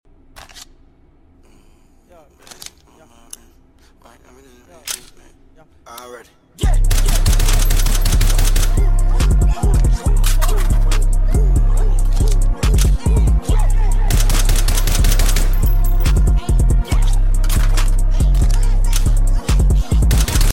Here’s some Mk.18 Reloads in the meantime!